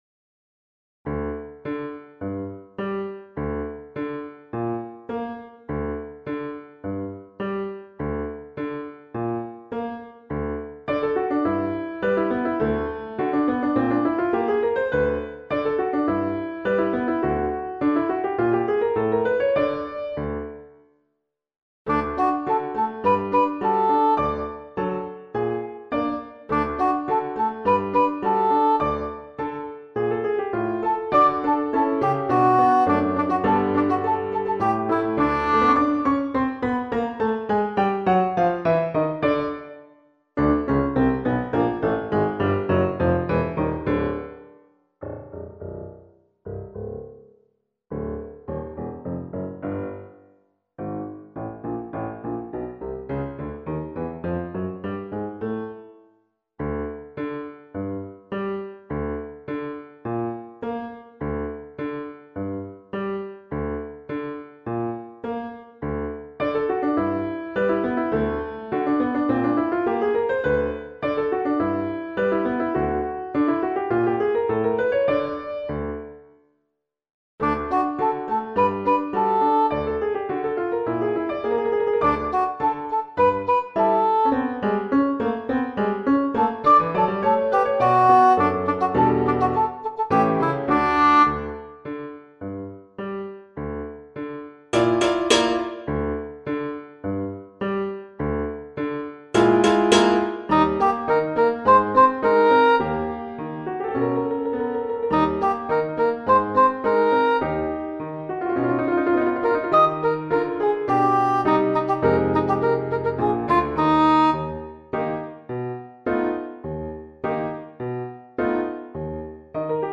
Chorale d'Enfants et Piano